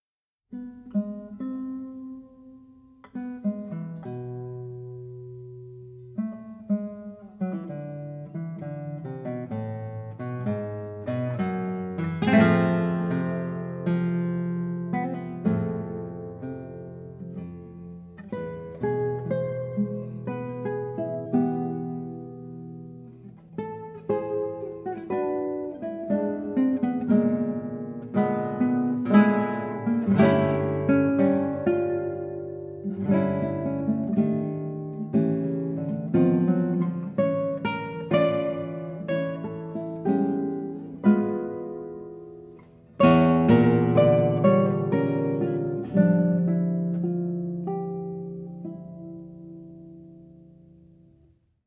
• Genres: Guitar, Classical
• Recorded at University of California, Santa Cruz
of pioneering African-American classical guitarists